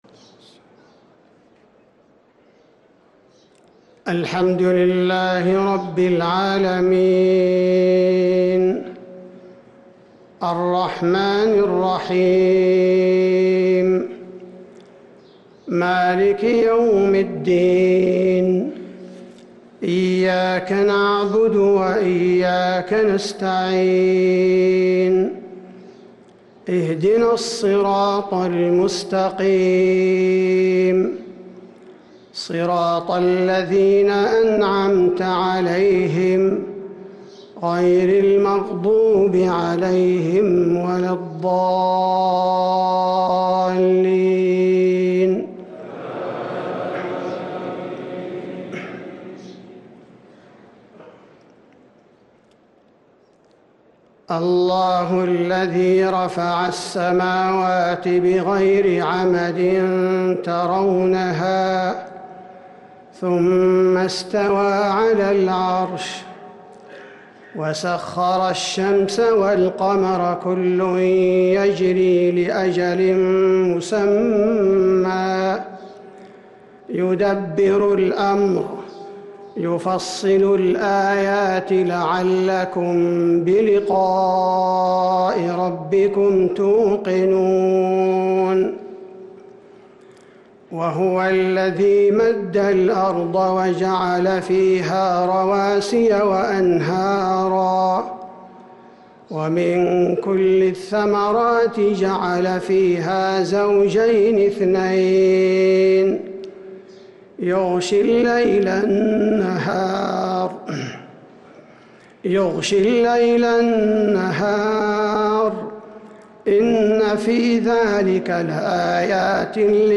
صلاة الفجر للقارئ عبدالباري الثبيتي 11 رمضان 1444 هـ
تِلَاوَات الْحَرَمَيْن .